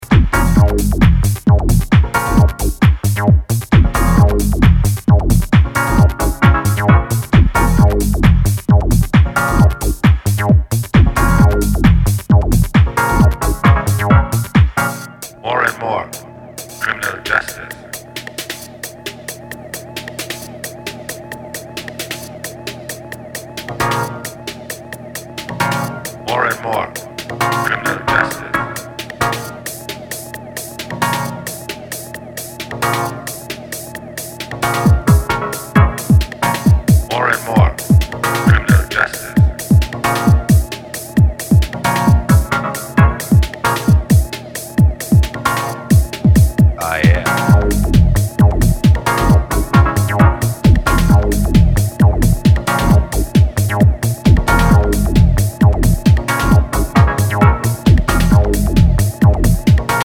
アシッドベースと跳ねたグルーヴの応酬が今EU圏でリヴァイバルしてる音ど真ん中であるだろう